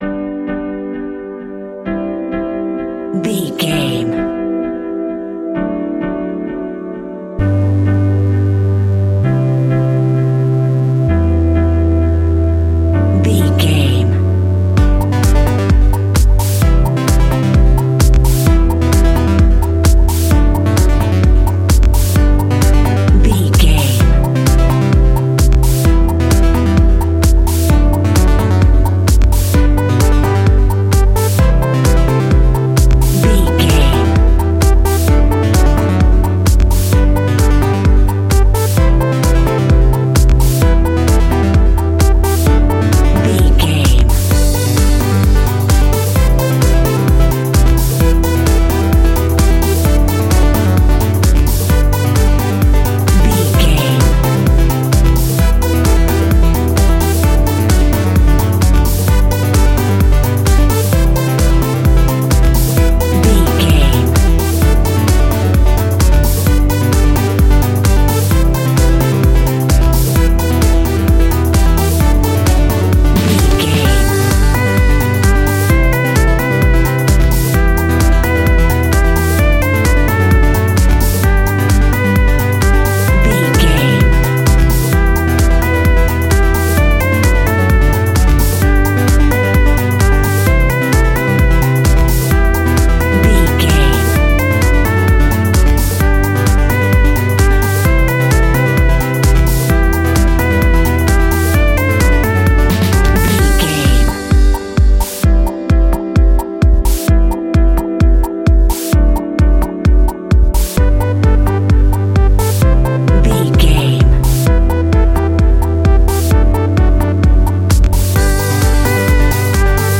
Ionian/Major
groovy
uplifting
futuristic
energetic
bouncy
piano
electric piano
synthesiser
drum machine
electronica
techno music
synth bass
synth pad
robotic